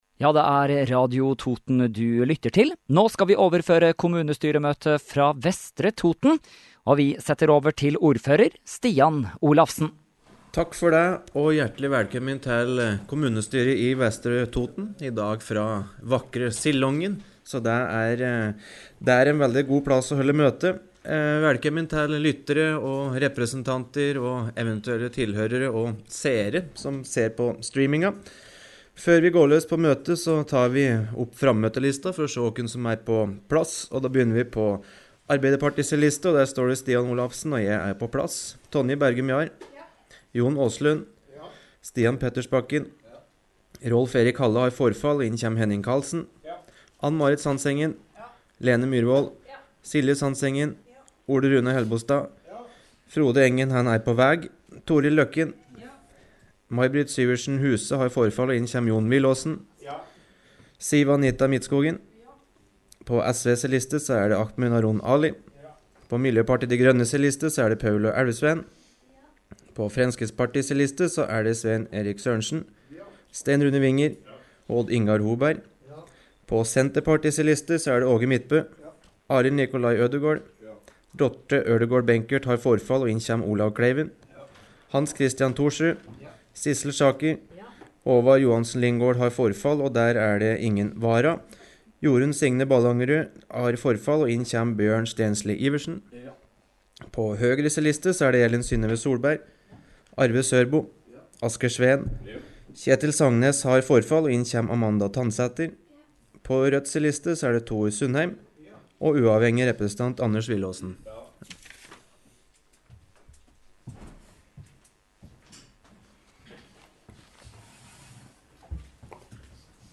Direkte overføring av Kommunestyremøte fra Vestre Toten 23. februar – Lydfiler Lagt Ut | Radio Toten
Møte sendes fra Sillongen